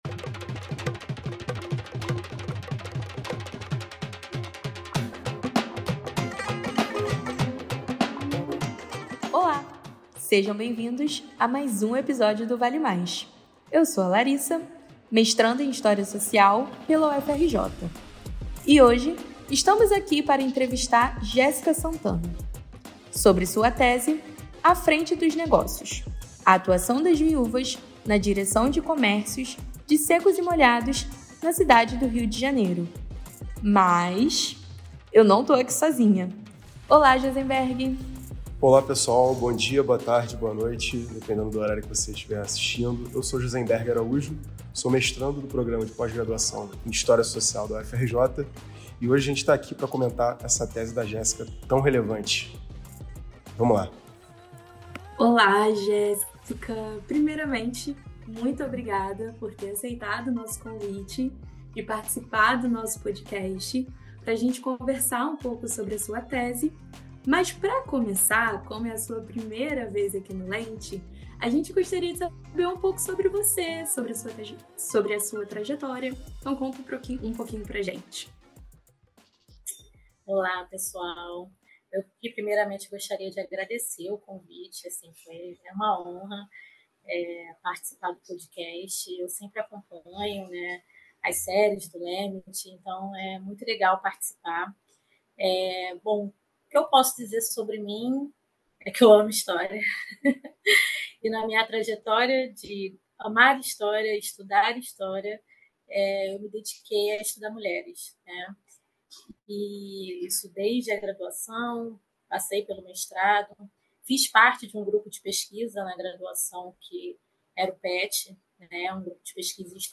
Nessa temporada, convidamos pesquisadores para discutir livros e teses recentes que aprofundam debates interdisciplinares sobre os mundos do trabalho.